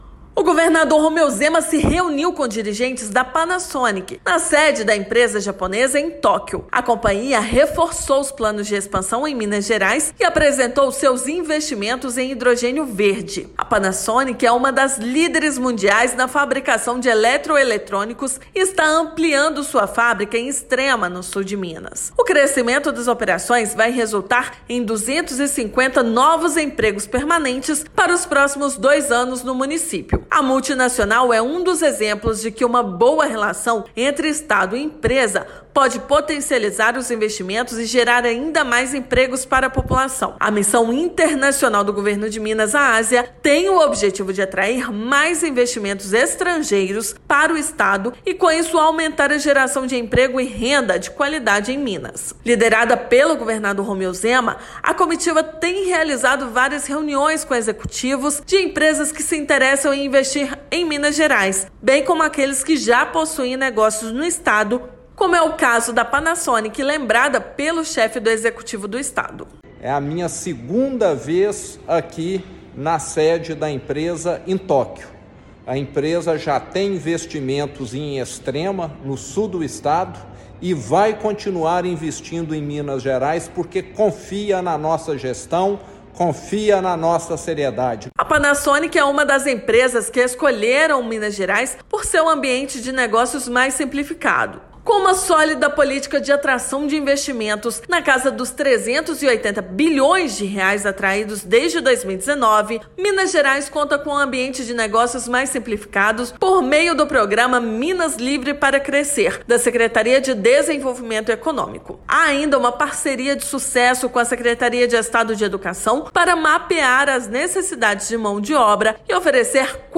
Empresa está expandindo sua fábrica em Extrema, no Sul do estado, abrindo 250 novos postos de trabalho. Ouça matéria de rádio.